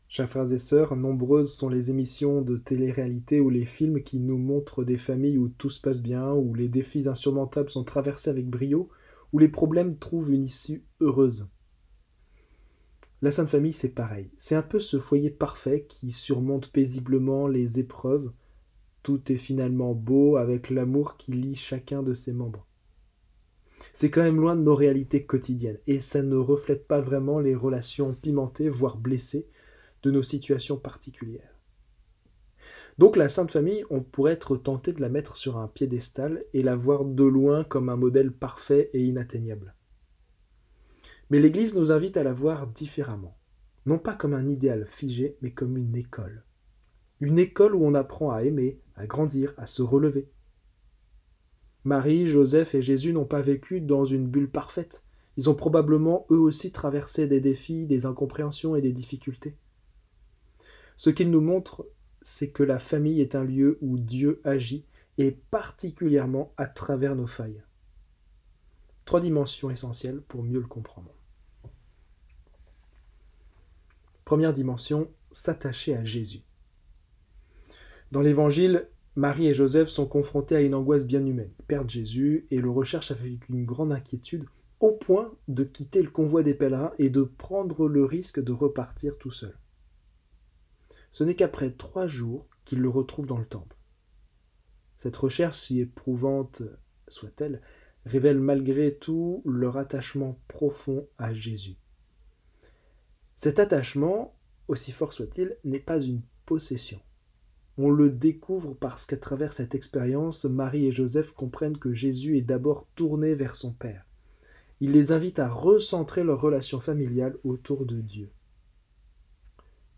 Homélies en 3 points